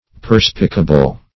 Search Result for " perspicable" : The Collaborative International Dictionary of English v.0.48: Perspicable \Per"spi*ca*ble\ (p[~e]r"sp[i^]*k[.a]*b'l), a. [L. perspicabilis, fr. perspicere.]